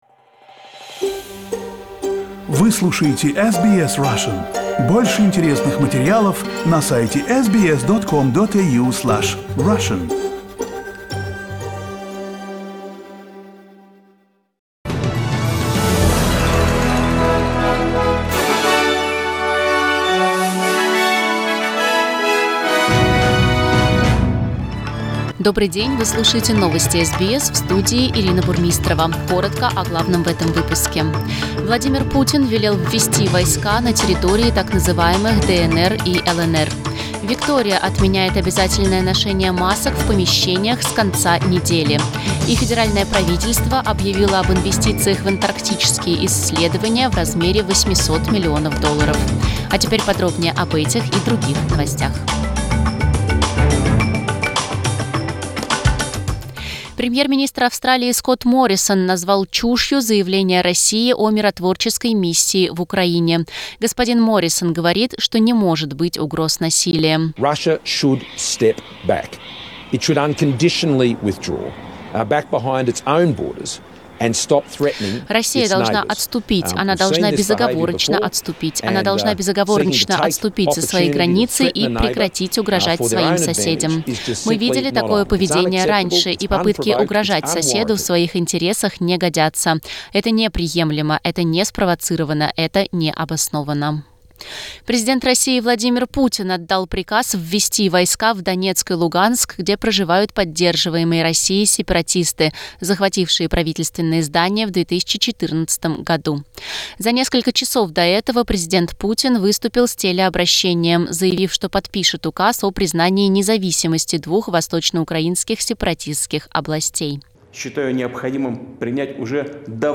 SBS news in Russian - 22.02